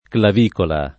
[ klav & kola ]